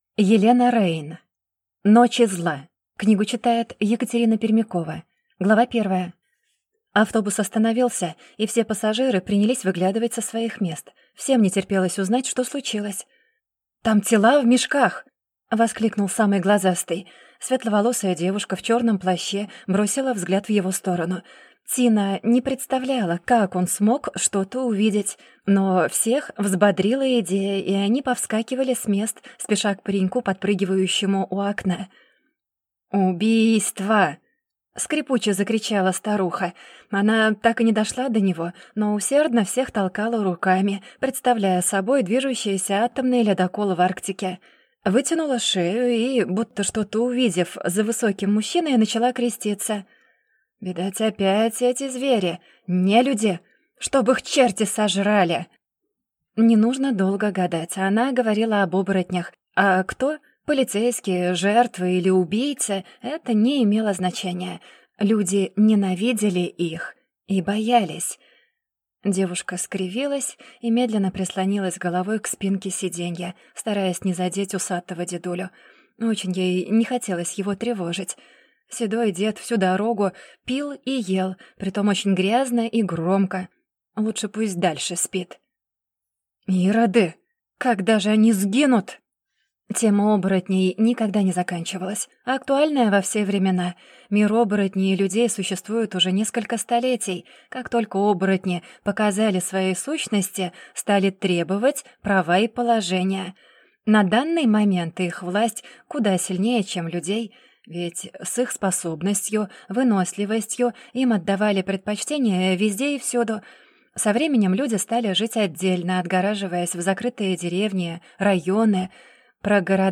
Аудиокнига Ночи Зла | Библиотека аудиокниг